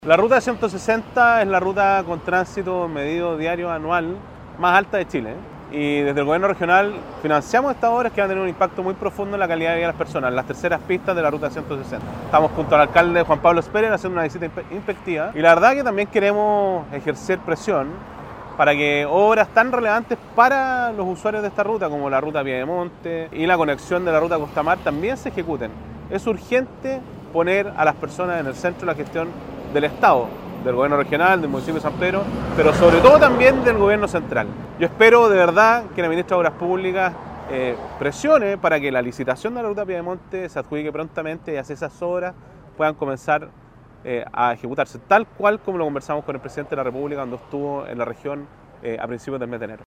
En ese sentido, el gobernador Sergio Giacaman, hizo un llamado a la ministra de Obras Públicas, Jéssica López, con el fin de apresurar el inicio de la obra en la ruta Pie de Monte.